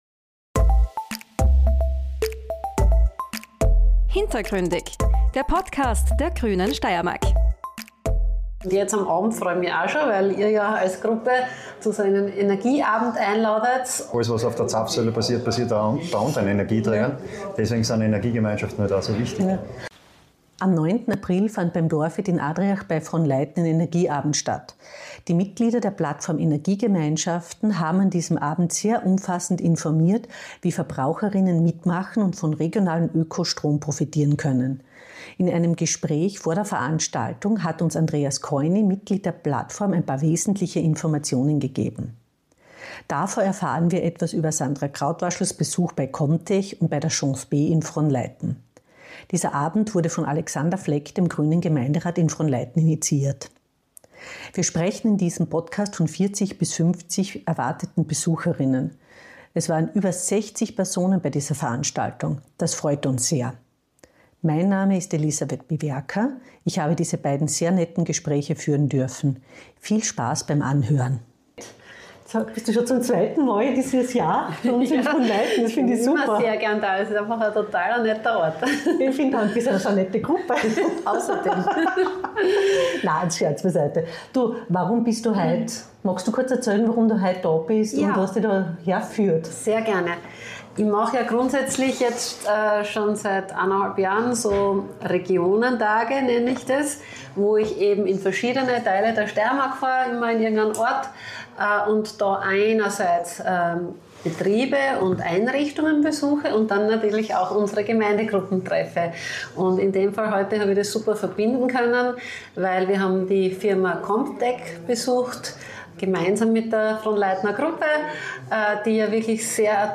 Beschreibung vor 1 Woche Am 9. April fand beim Dorfwirt in Adriach in der Gemeinde Frohnleiten ein Energieabend statt. Die Mitglieder der Plattform Energiegemeinschaften haben an diesem Abend sehr umfassend informiert, wie Verbraucher:innen mitmachen und von regionalem Ökostrom profitieren können.